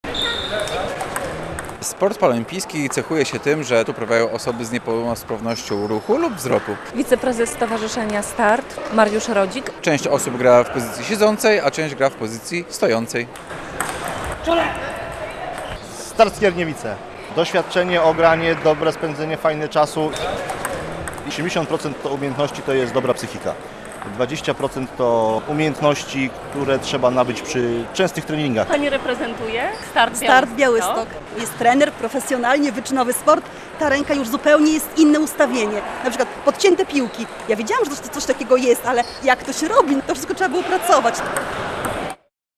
Około 160 osób rywalizuje w Białymstoku w tenisie stołowym - trwa II Grand Prix Polski Osób Niepełnosprawnych - relacja